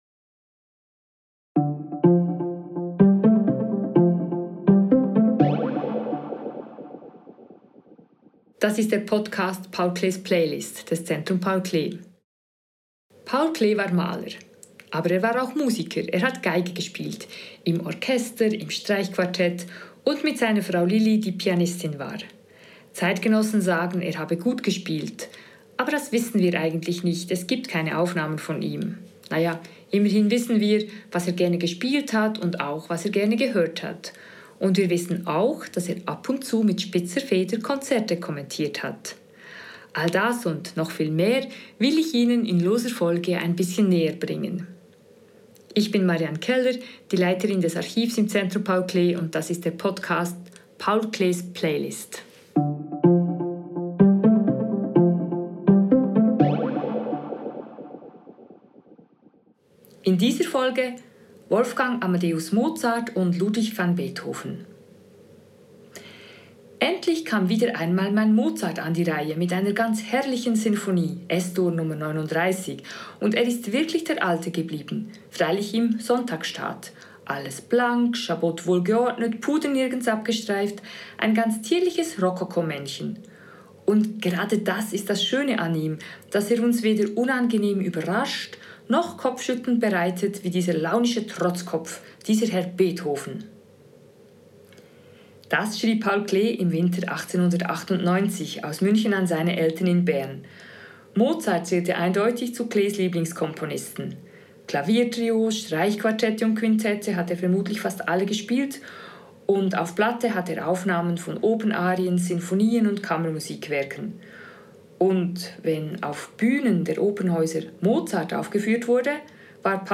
Was Paul Klee dazu sagte, erfahren Sie in dieser Folge von «Paul Klees Playlist». Gespielte Werke (Ausschnitte): Wolfgang Amadeus Mozart, Ouvertüre aus der Oper «Le nozze di Figaro», Wolfgang Amadeus Mozart, Violinsonate A-Dur KV 526 Wolfgang Amadeus Mozart, Sinfonie Nr. 41 KV 551, «Jupiter-Sinfonie» Ludwig van Beethoven, Violinromanze Nr. 2 F-Dur op. 50 Ludwig van Beethoven, Sinfonie Nr. 7 A-Dur op. 92 Ludwig van Beethoven, Streichquartett Nr. 14 cis-Moll op. 131 Sprecherin